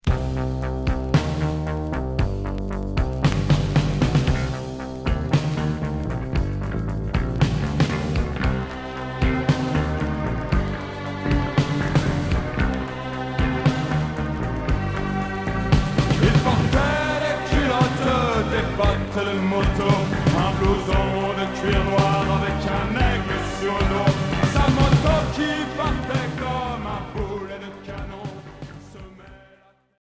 Cold wave Premier 45t retour à l'accueil